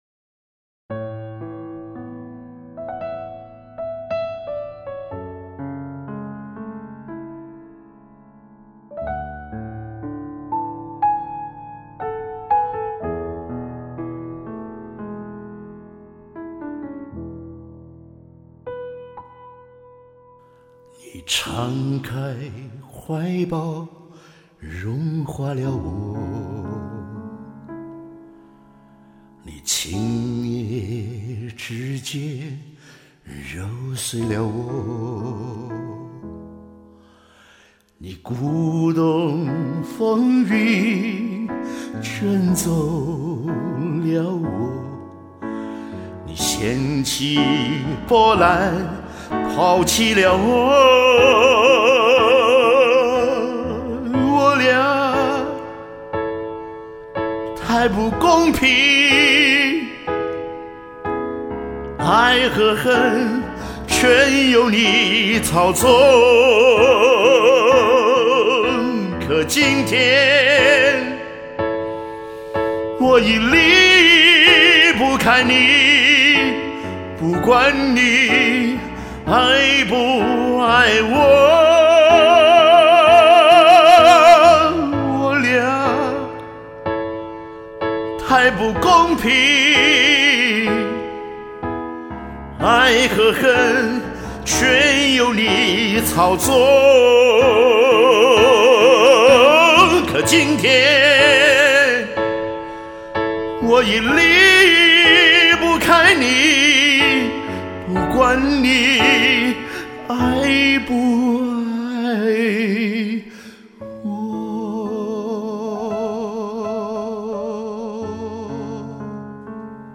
非常喜欢钢琴伴奏